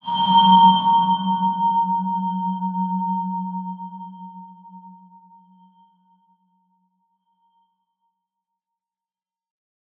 X_BasicBells-F#1-ff.wav